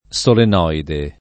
solenoide [ S olen 0 ide ]